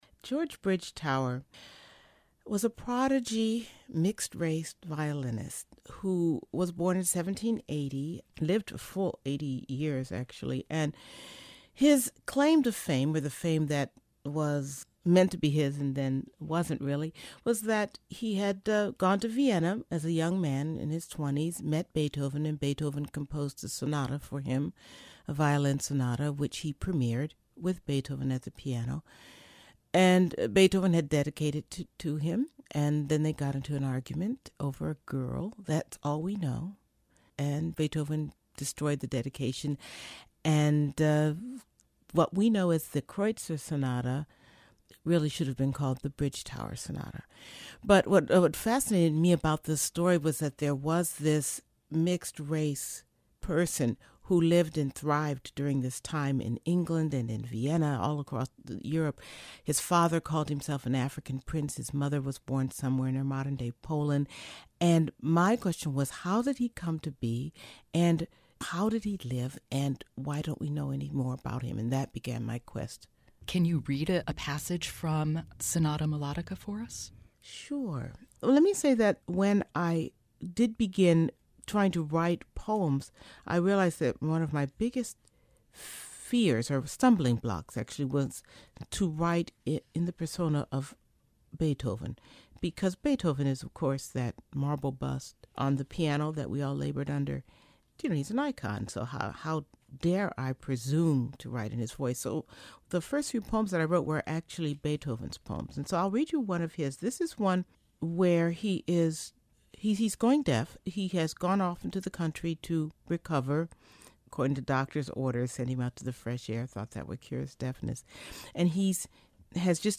In this excerpt from the podcast, Dove talks about Bridgetower and reads a poem from Sonata Mulattica.